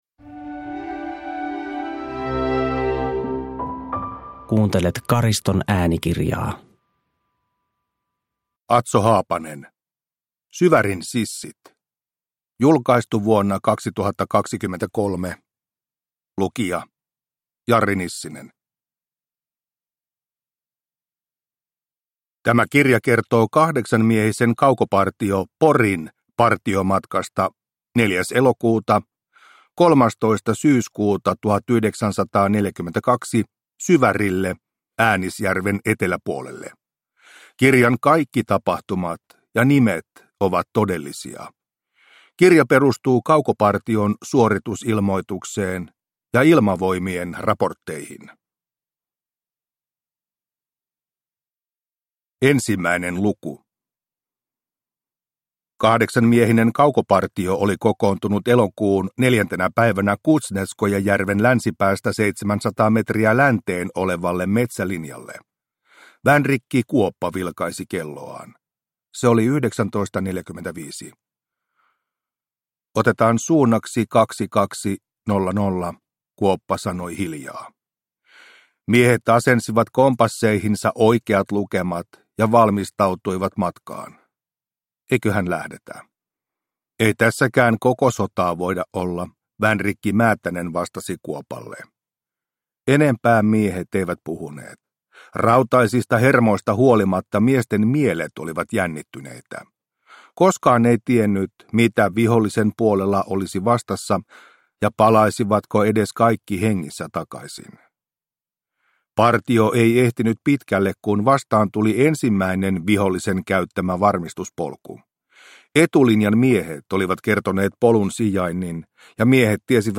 Syvärin sissit – Ljudbok – Laddas ner